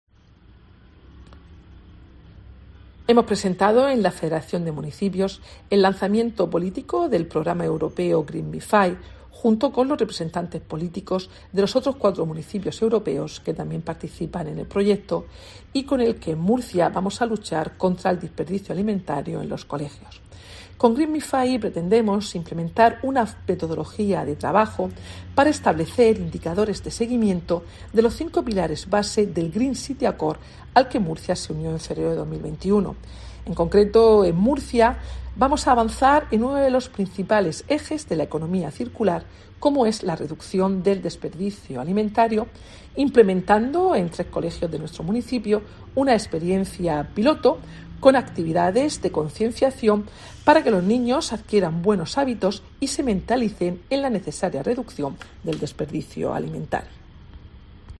Mercedes Bernabé, concejala de Gobierno Abierto, Promoción Económica y Empleo